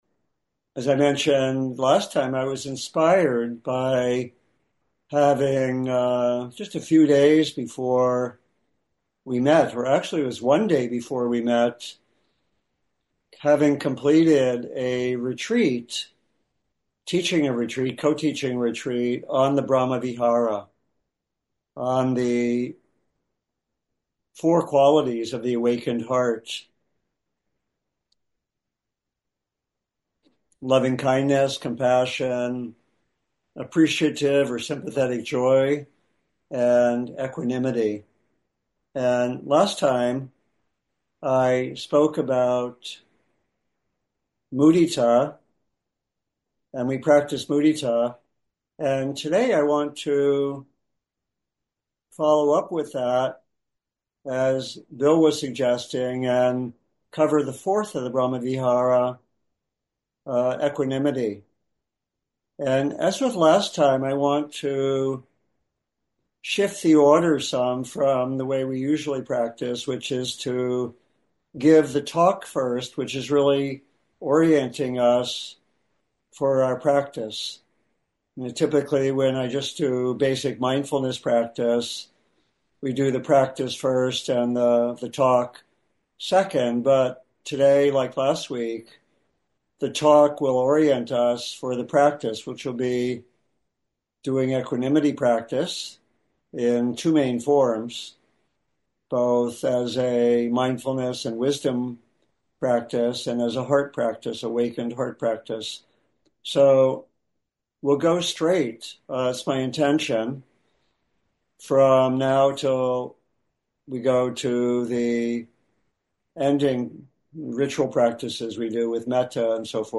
Equanimity Practice: Talk, Practice Period, and Discussion